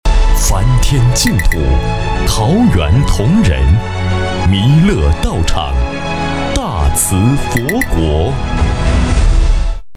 语言：普通话 （155男）
特点：大气浑厚 稳重磁性 激情力度 成熟厚重
央视广告-贵州梵净山.MP3